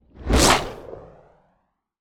bullet_flyby_designed_01.wav